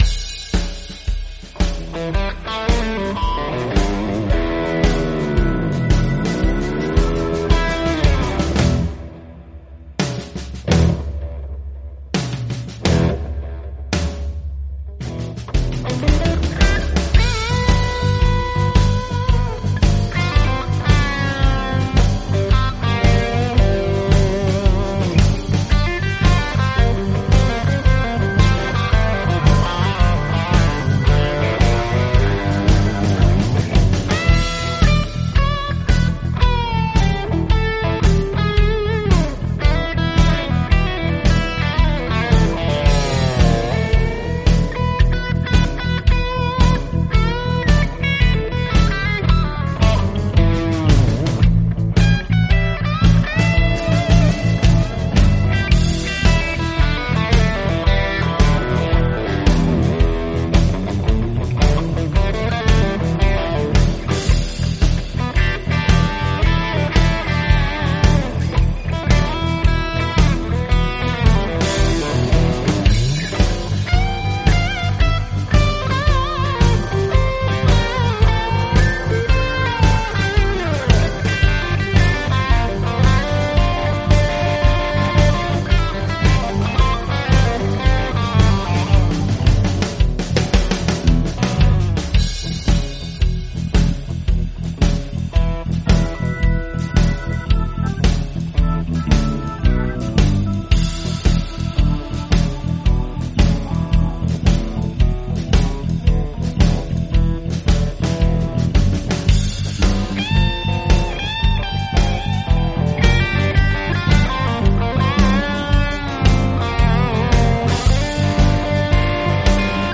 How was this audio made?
Recorded, mixed and mastered in Presonus Studio One 4.6 at home Studio in San Luis Obispo, Ca.